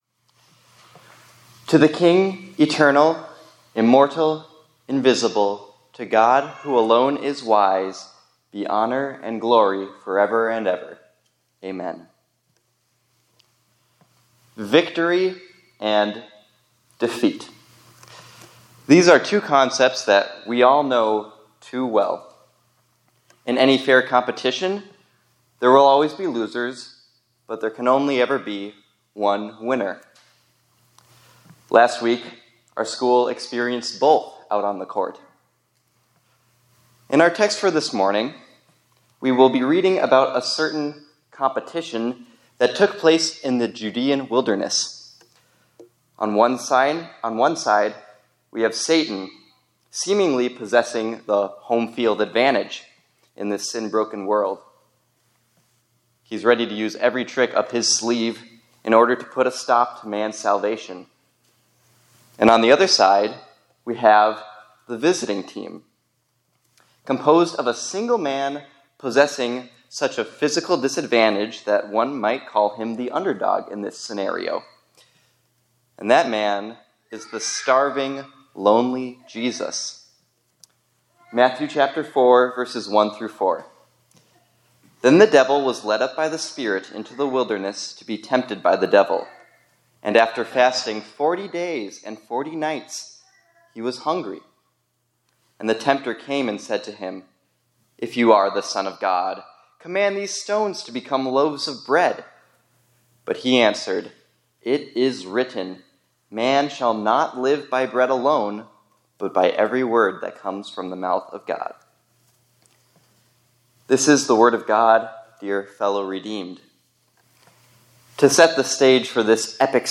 2025-03-10 ILC Chapel — We Are Champions in Christ